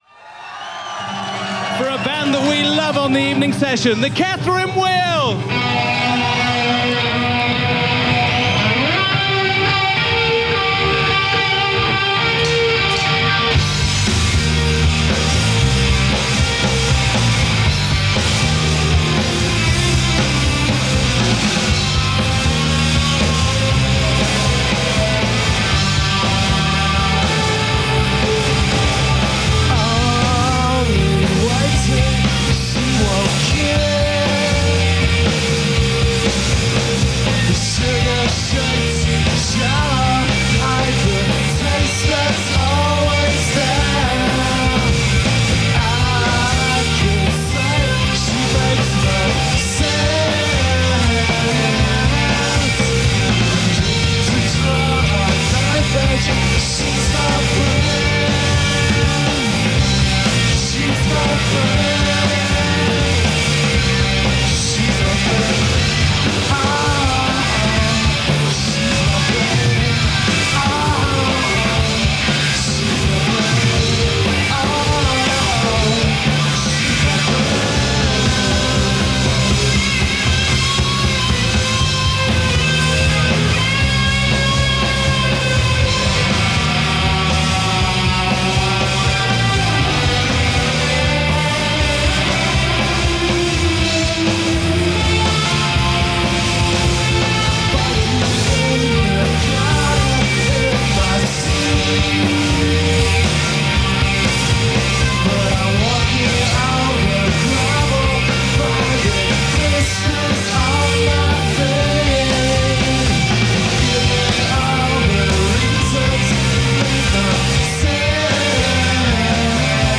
recorded live at Norwich Waterfront